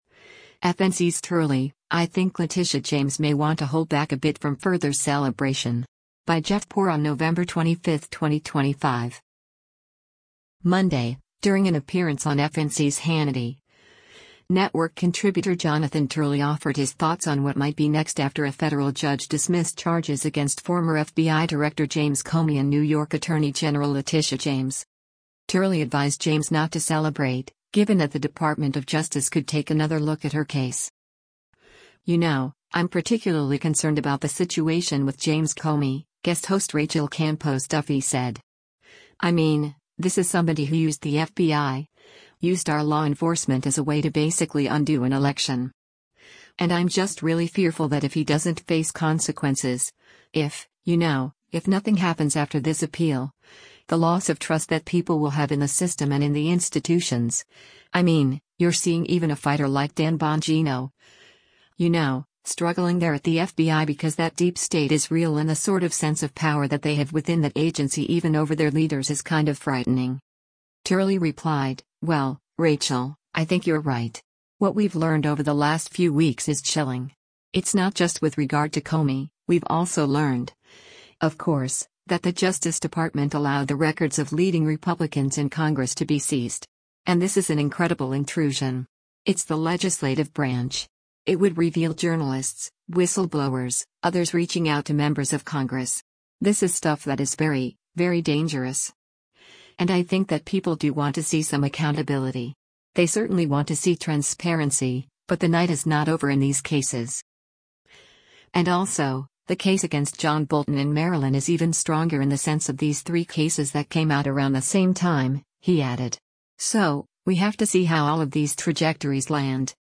Monday, during an appearance on FNC’s “Hannity,” network contributor Jonathan Turley offered his thoughts on what might be next after a federal judge dismissed charges against former FBI Director James Comey and New York Attorney General Letitia James.